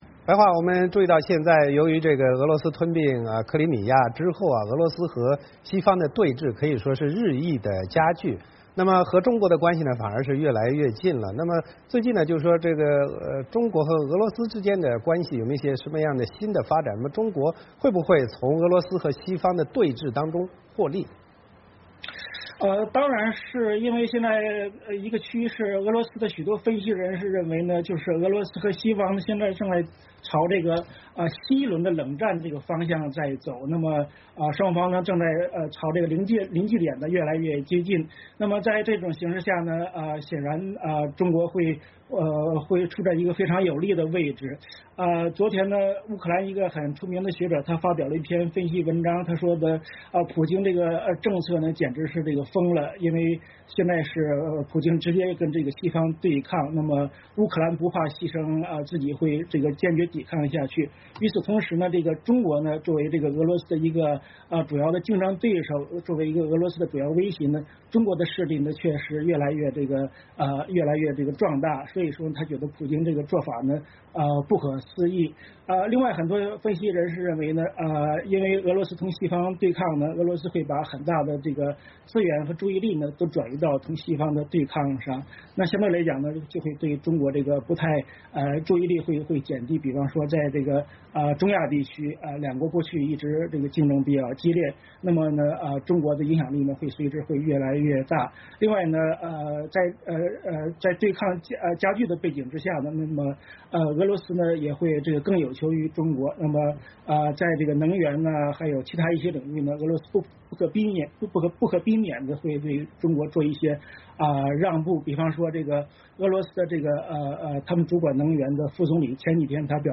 VOA连线：俄罗斯与西方对峙，中国从中获利？